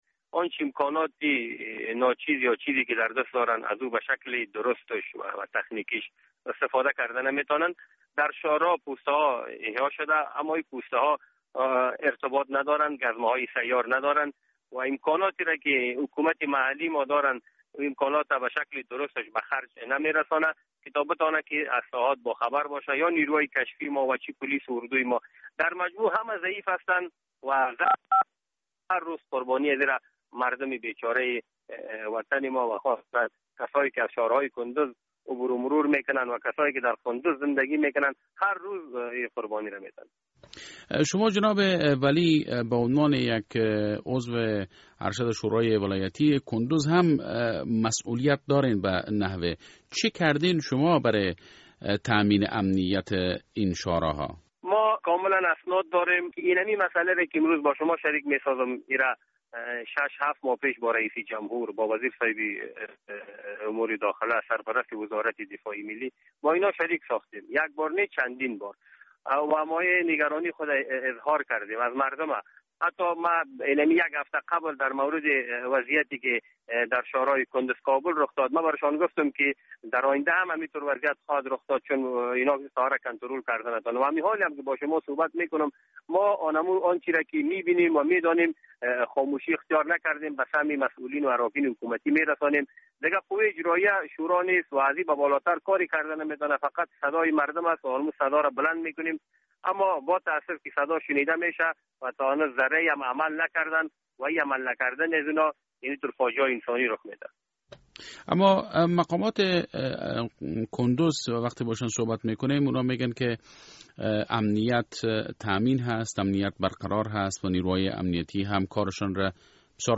مصاحبه - صدا
مصاحبه با امرالدین ولی عضو شورای ولایتی کندز: